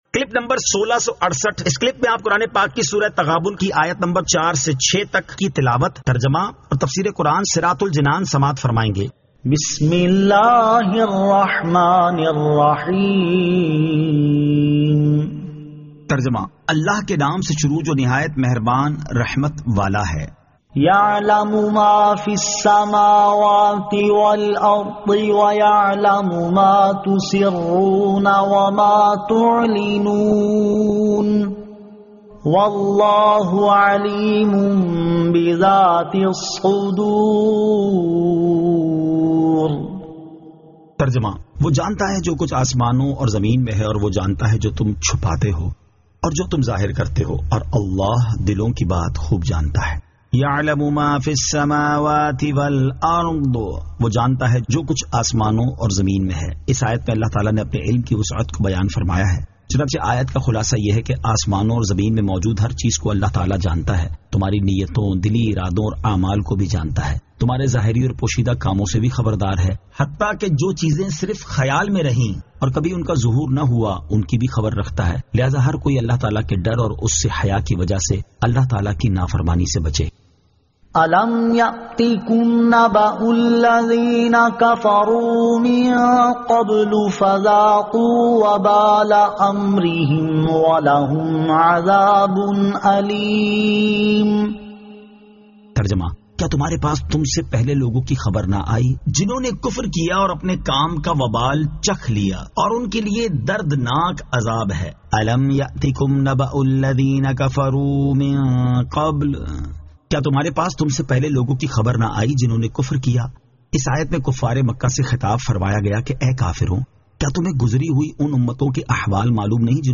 Surah At-Taghabun 04 To 06 Tilawat , Tarjama , Tafseer